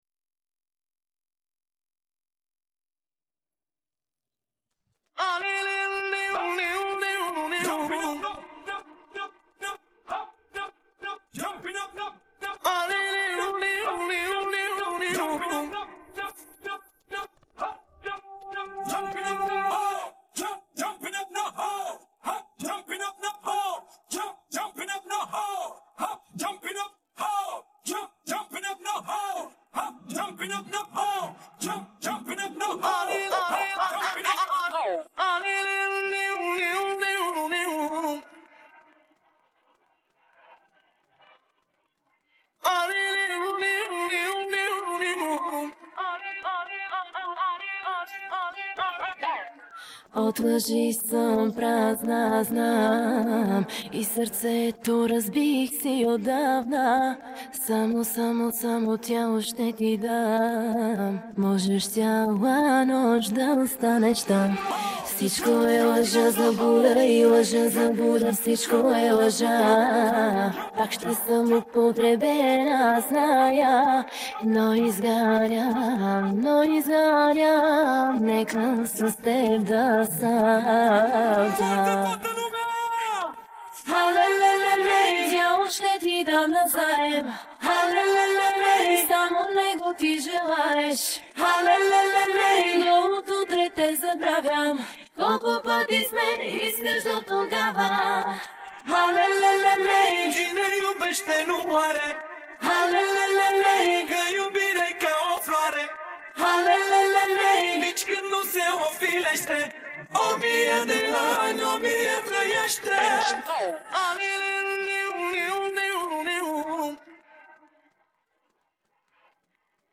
Bagian Vokal